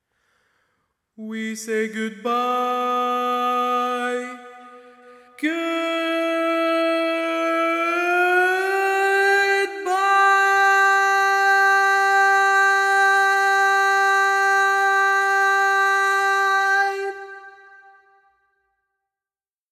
Type: Barbershop
Each recording below is single part only.
Learning tracks sung by